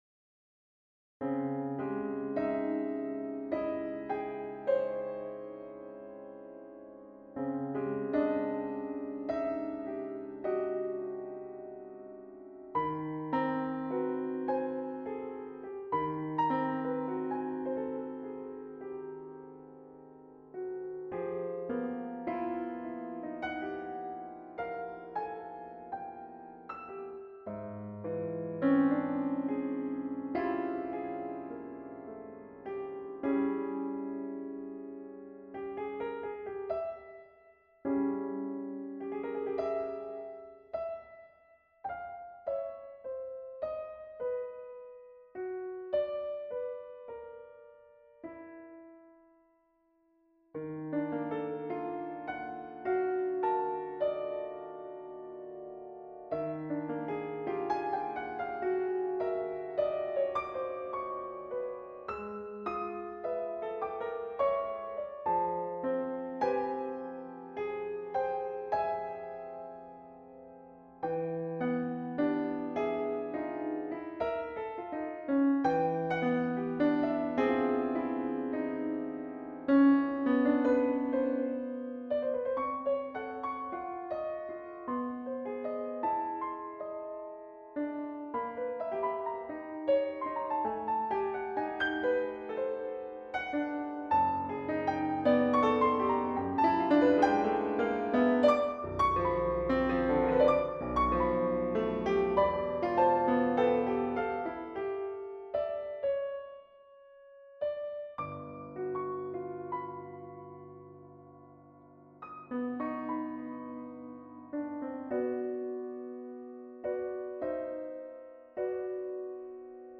Unperformed work, so just computer realisations (my apologies).